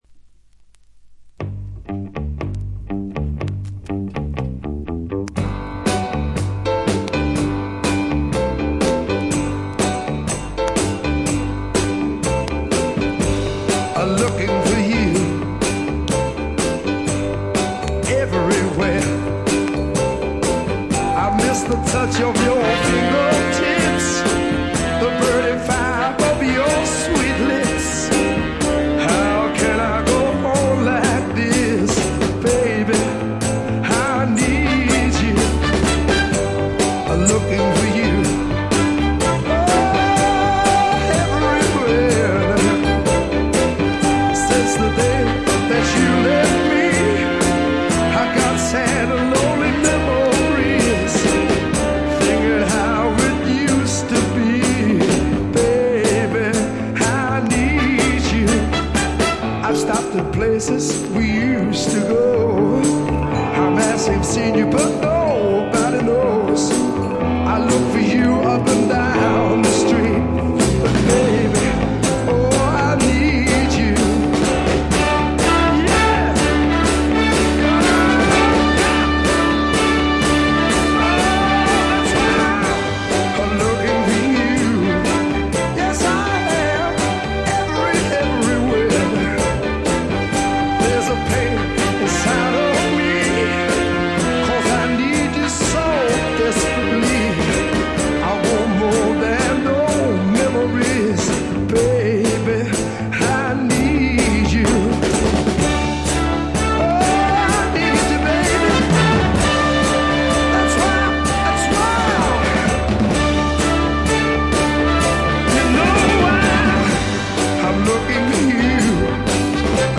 ところどころでチリプチ、バックグラウンドノイズ。特に気になるような大きなノイズはありません。
試聴曲は現品からの取り込み音源です。